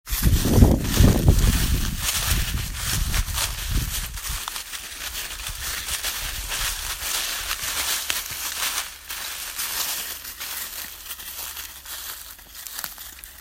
Sound 2 = Leaves crunching underfoot